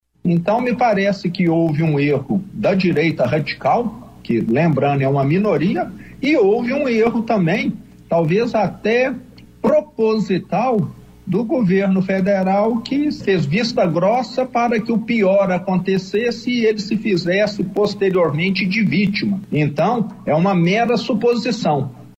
Na manhã desta segunda-feira,16/01, o Governador de Minas Gerais, Romeu Zema, disse em entrevista à Rádio Gaúcha que em sua opinião, o governo Lula, deixou os ataques em Brasília acontecerem para se fazer de vítima.
Trecho da entrevista à Rádio Gaúcha